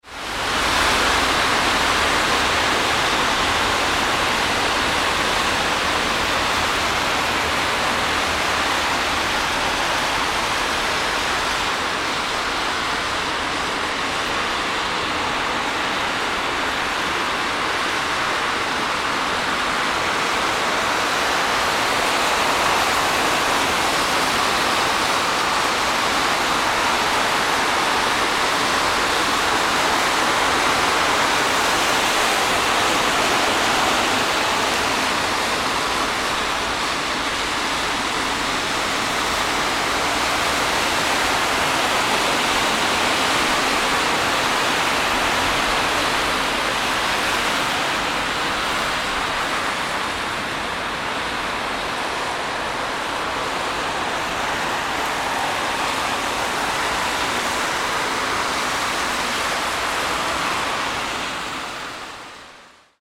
Car Tire Splashing On Wet Road Sound Effect
Description: Car tire splashing on wet road sound effect captures the realistic noise of tires rolling over damp asphalt. Add this sound effect to films, videos, or games to create authentic rainy street ambience.
Car-tire-splashing-on-wet-road-sound-effect.mp3